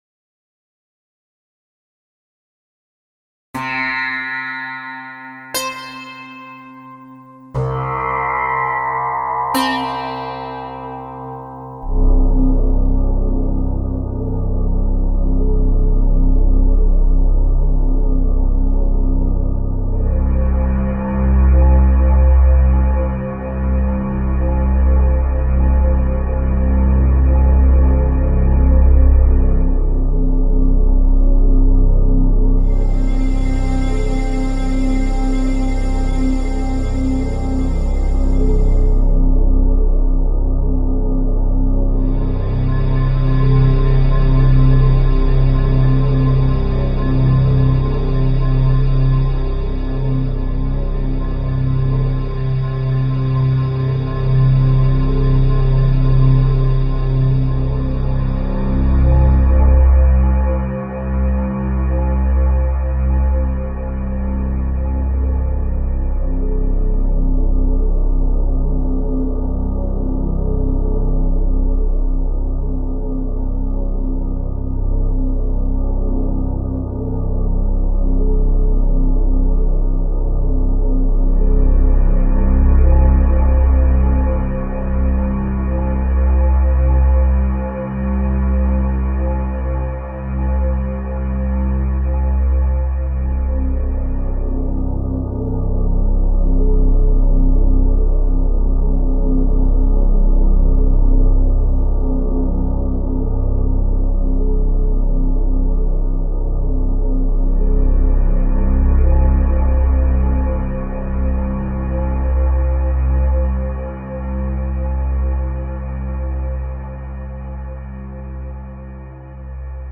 ambient et relaxant